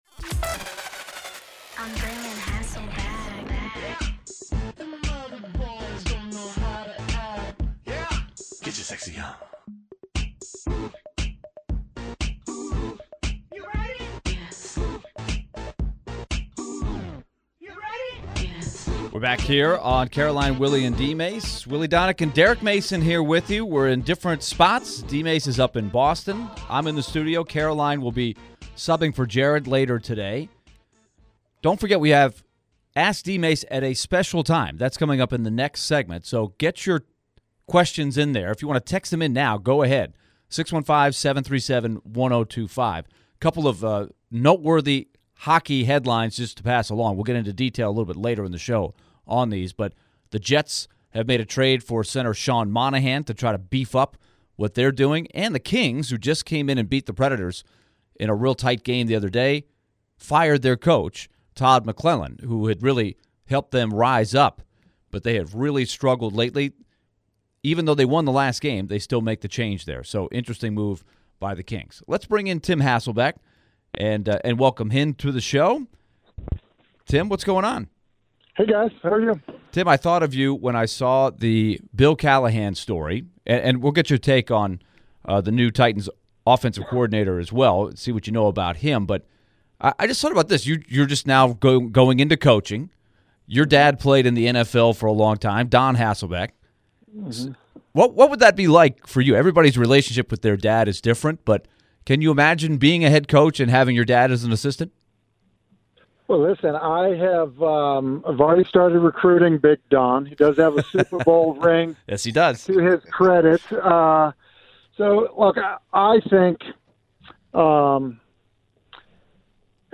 ESPN’s NFL Analyst and new Ensworth head coach joined the show and shared his thoughts about the new additions to the Titans coaching staff. Can Bill Callahan improve the Titans offensive line? Is it odd to see Bill Callahan on Brian Callahan’s staff?